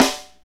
Index of /90_sSampleCDs/Northstar - Drumscapes Roland/SNR_Snares 1/SNR_H_H Snares x